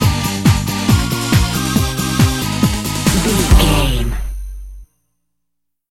Uplifting
Aeolian/Minor
Fast
drum machine
synthesiser
electric piano
Eurodance